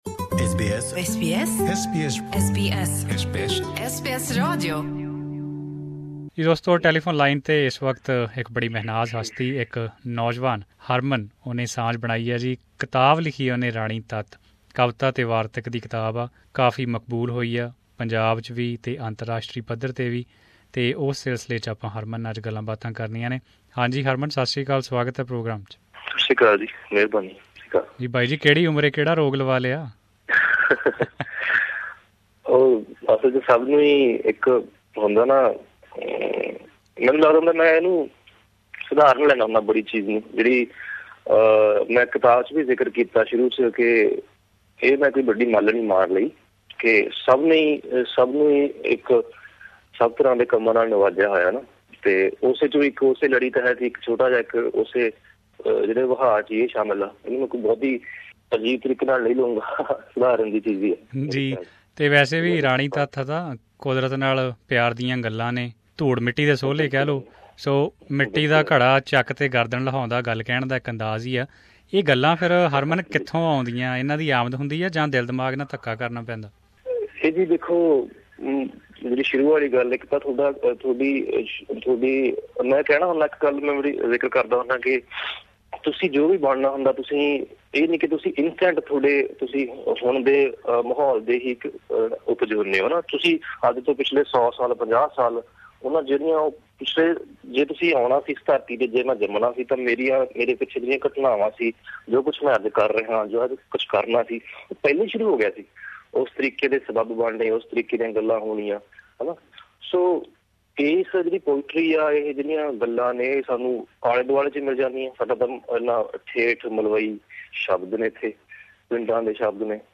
Here he is in conversation